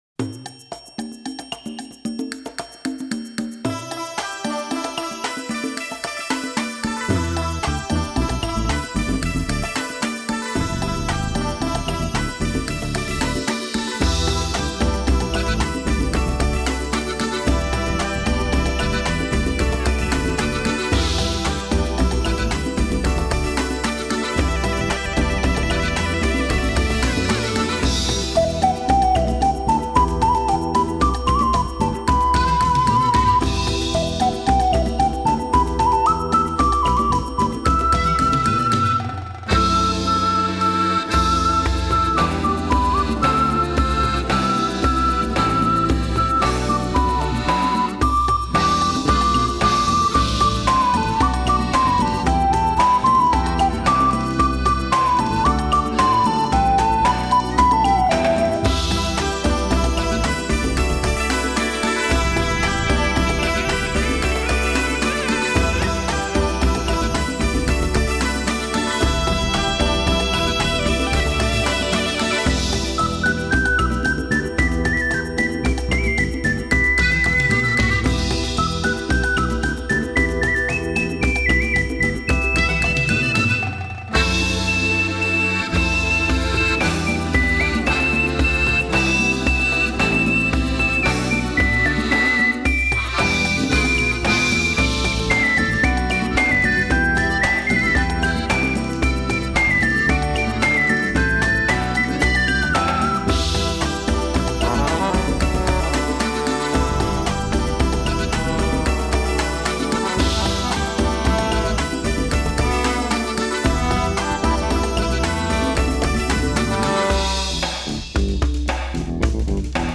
Genre: Newage.